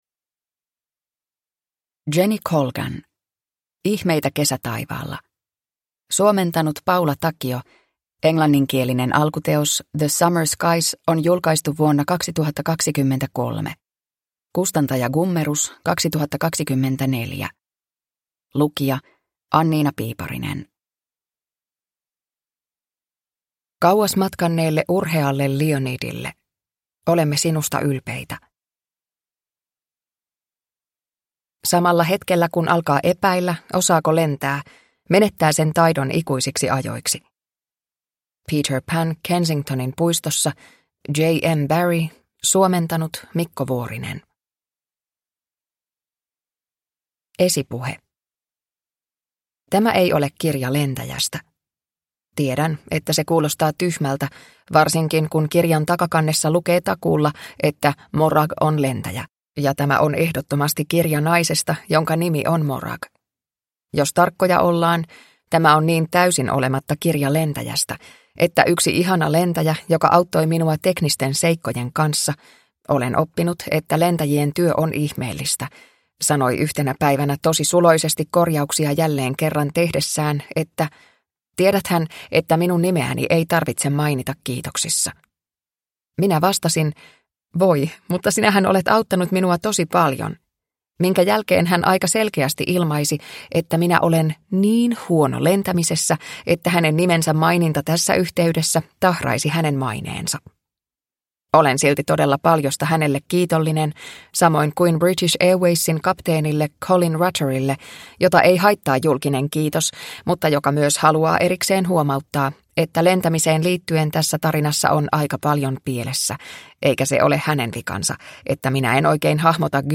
Ihmeitä kesätaivaalla (ljudbok) av Jenny Colgan